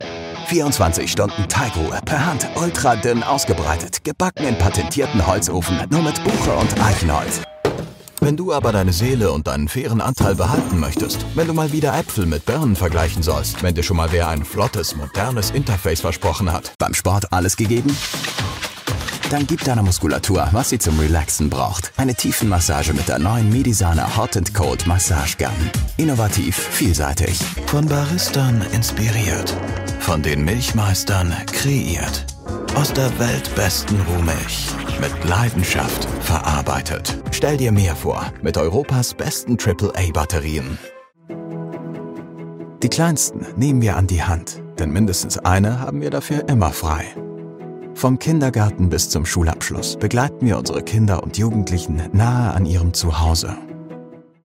German Commercial Reel
An authentic, real narrative voice.
Commercial Demo Reel 2024.mp3